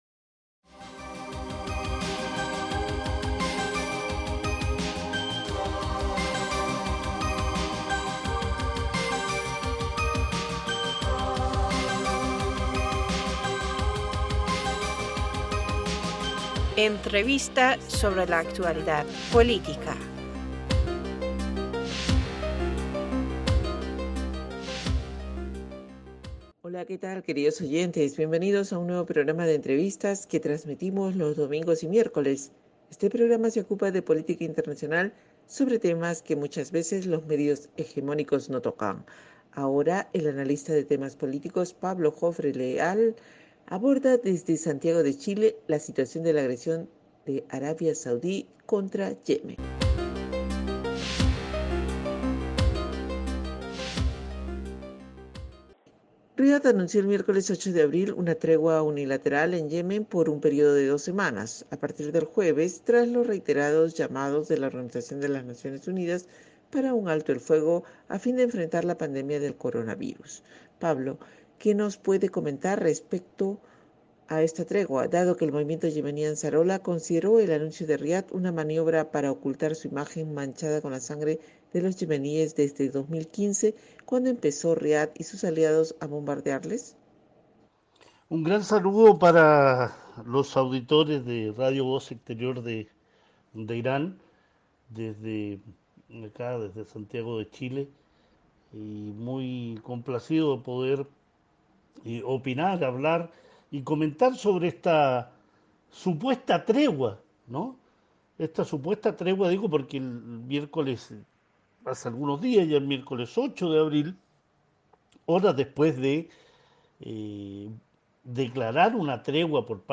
Parstoday- Entrevistador (E): Hola qué tal queridos oyentes bienvenidos a un nuevo programa de Entrevistas, que trasmitimos los domingos y miércoles.
Este programa se ocupa de política internacional sobre temas que muchas veces los medios hegemónicos no tocan.